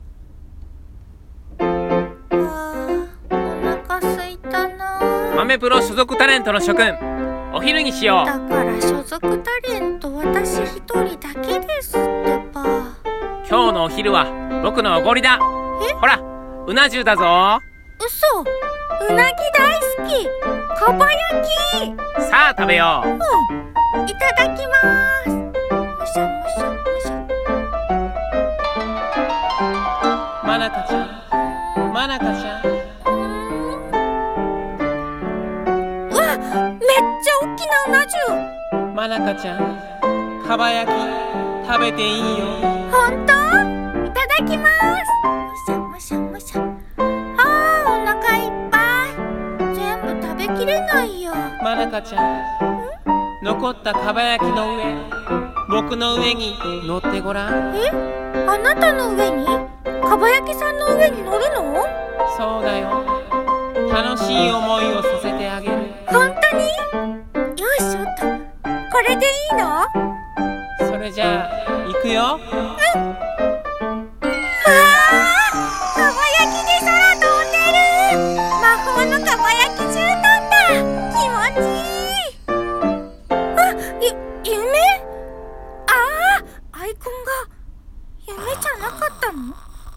二人声劇「豆プロのランチタイム